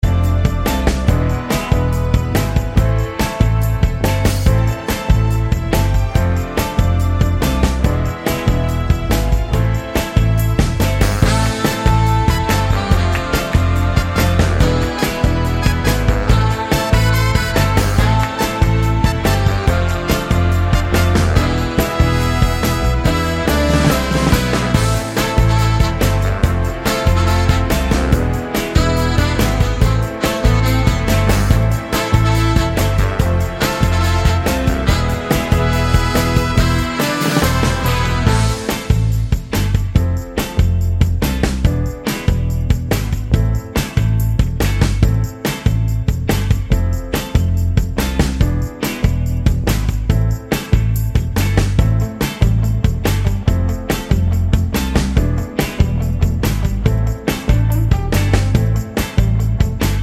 no Backing Vocals Pop (2010s) 3:35 Buy £1.50